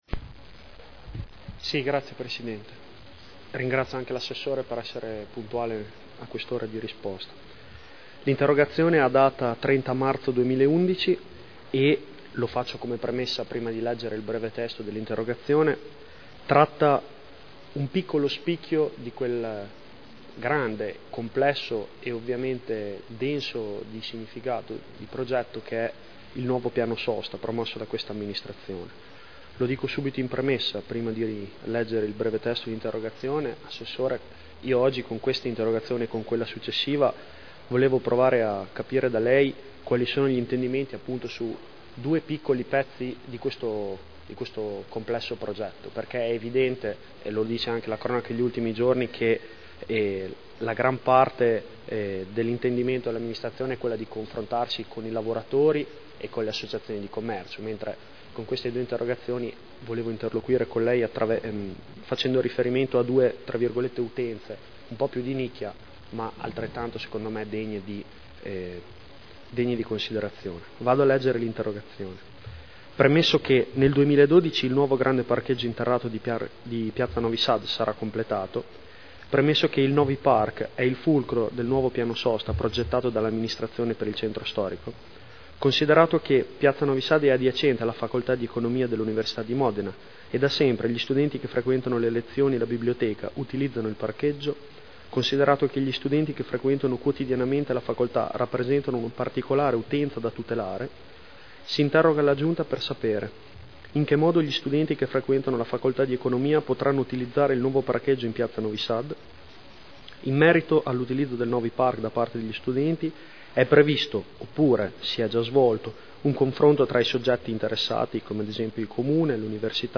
Giulio Guerzoni — Sito Audio Consiglio Comunale